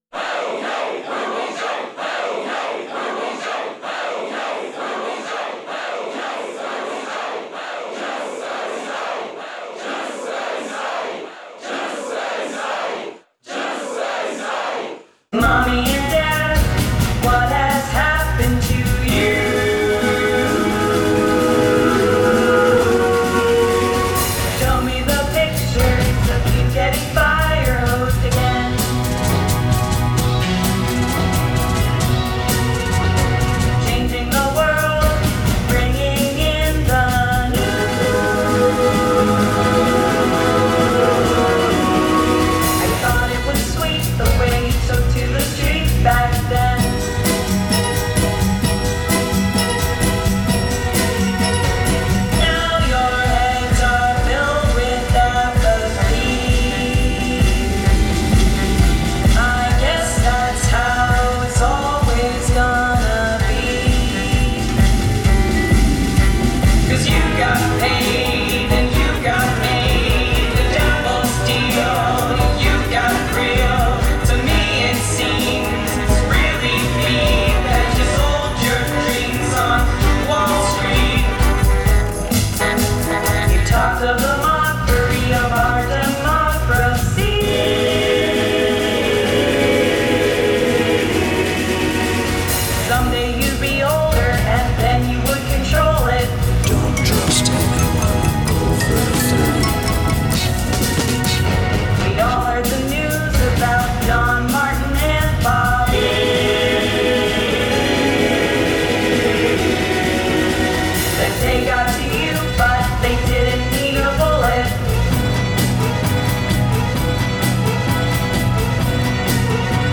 正真正銘のサイケデリック、幻惑的なサウンドスケープ。
きらびやかで壮大で劇的な曲の展開が好きな人にはたまらないかも。